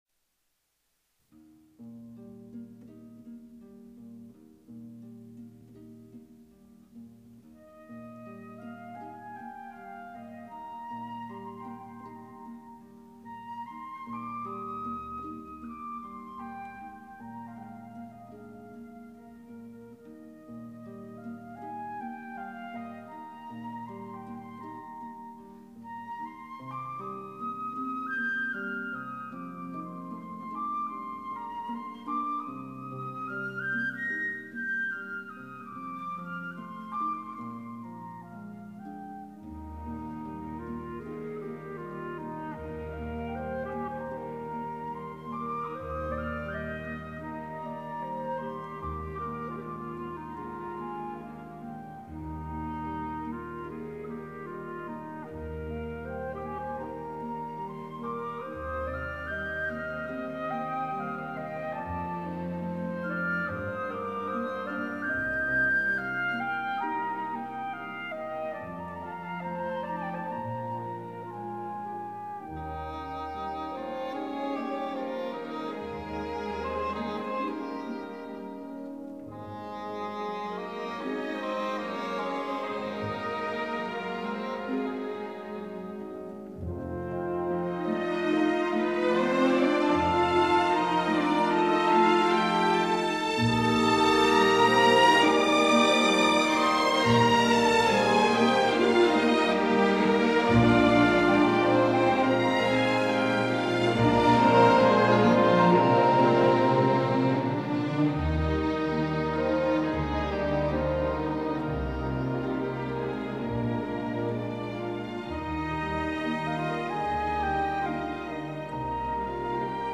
本曲选自歌剧《卡门》中第二幕与第三幕之间的间奏曲，这是一段轻柔、优美的旋律,长笛与竖琴交相辉映，饱含脉脉的温情。